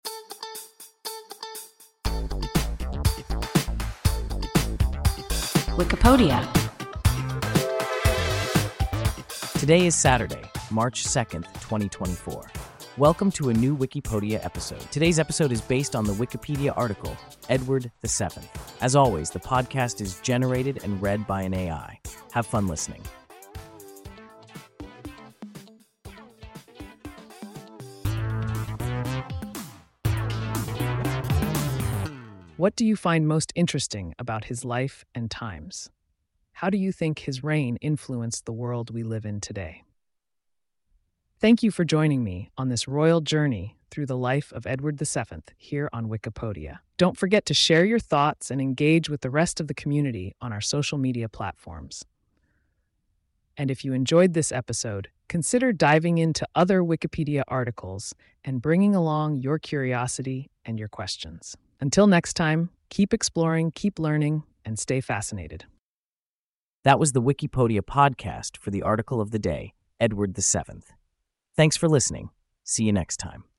Edward VII – WIKIPODIA – ein KI Podcast